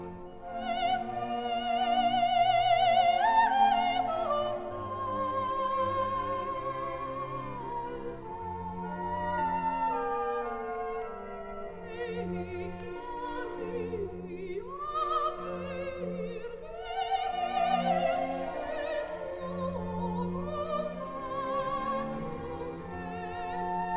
soprano
mezzo-soprano
tenor
bass
1958 stereo recording